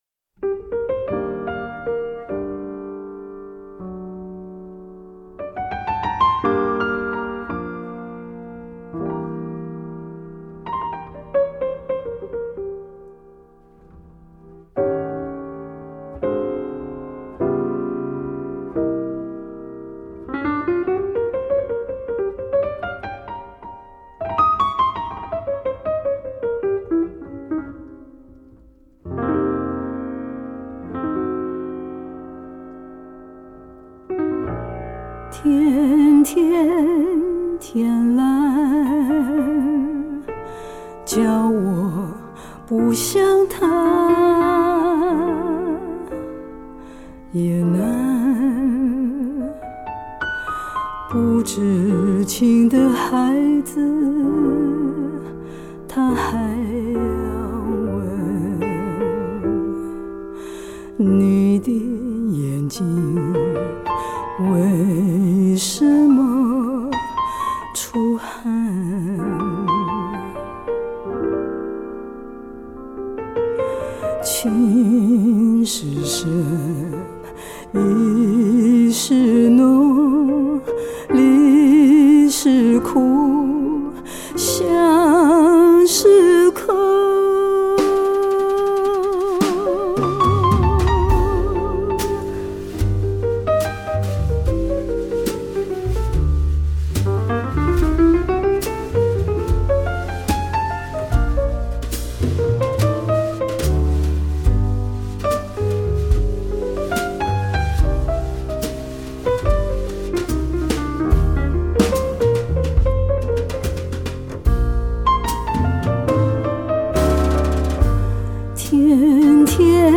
并找来巴拉圭吉他手伴奏，十首歌曲不经剪接、一路到底。
整张专辑音调降低，节奏也放慢，既古典又爵士，很有的感觉。